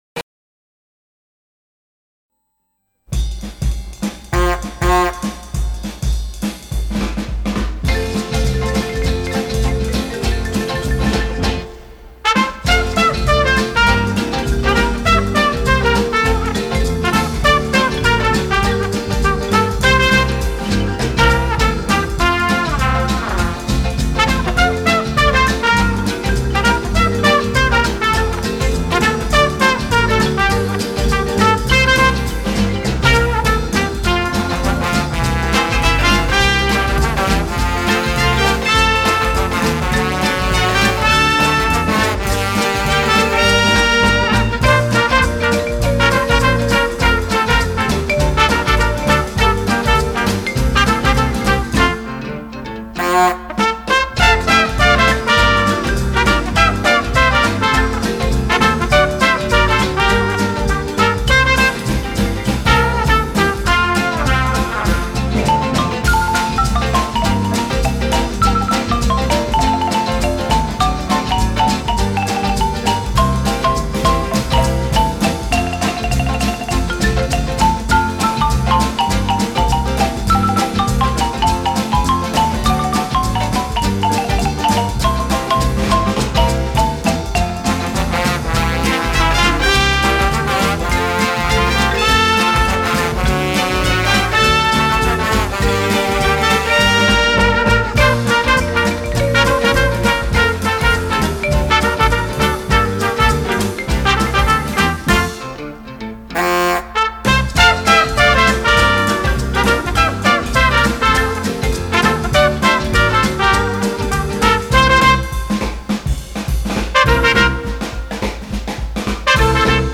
Eguerdiko Ahotsa magazine, informatibo, giza-kultural bat da, eta bertan prentsa errepasoa, elkarrizketak, kaleko iritziak, kolaboratzaileen kontakizunak, musika, agenda eta abar topatu ditzazkezu.